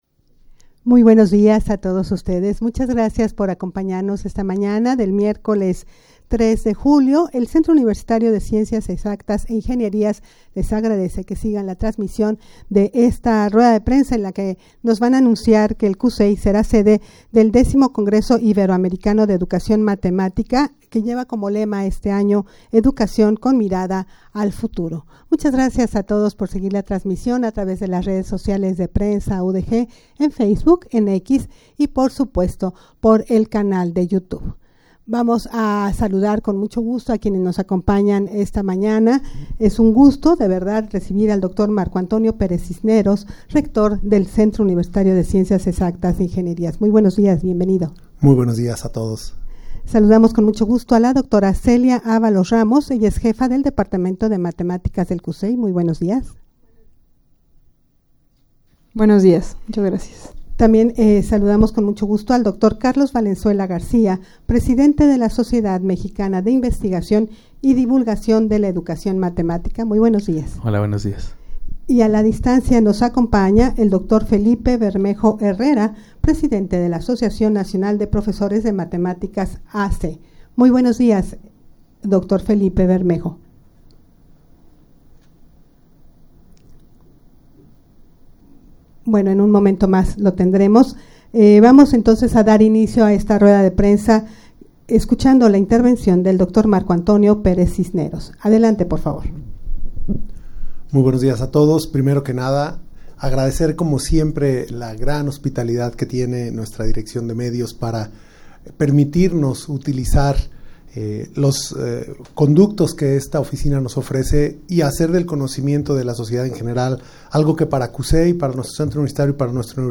Audio de la Rueda de Prensa
rueda-de-prensa-para-anunciar-que-sera-sede-del-x-congreso-iberoamericano-de-educacion-matematica.mp3